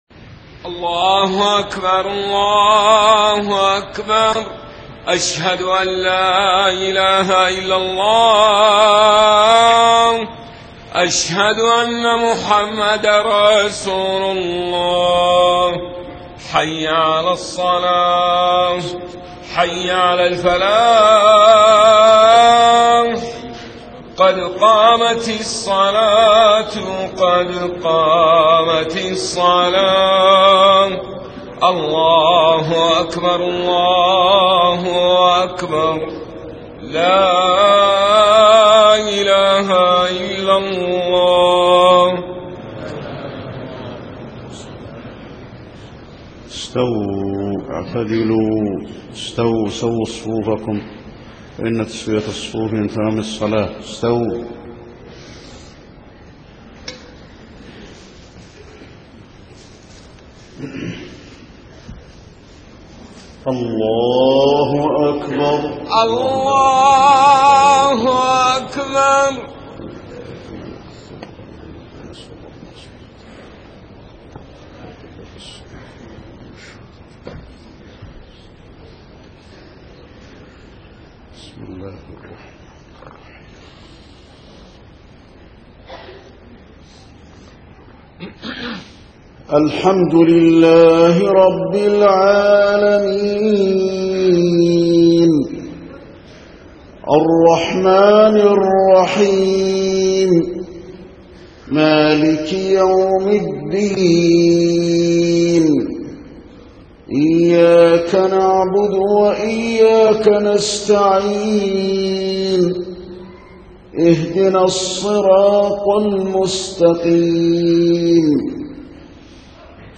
صلاة العشاء 23 محرم 1430هـ فواتح سورة الواقعة 1-40 > 1430 🕌 > الفروض - تلاوات الحرمين